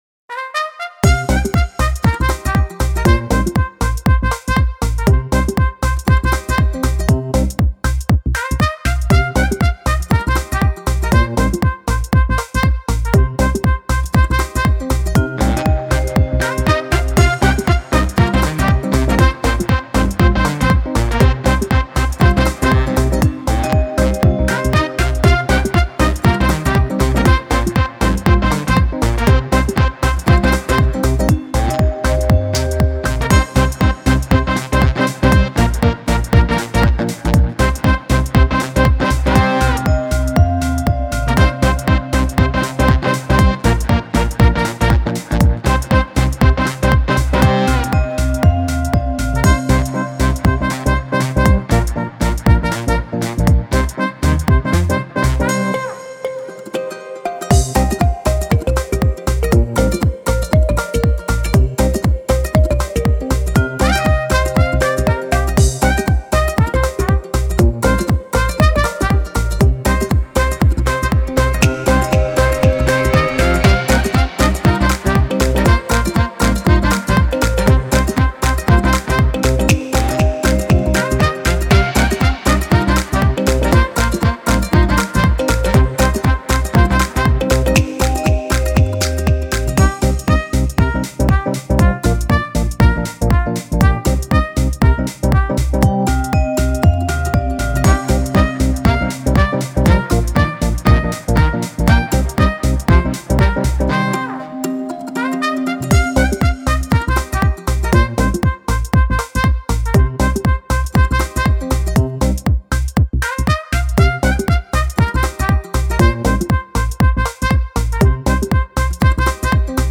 活动BGM